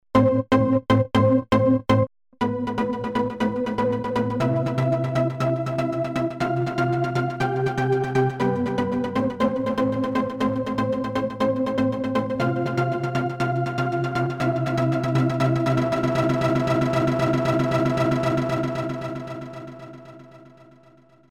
delay effect
delay-synth.mp3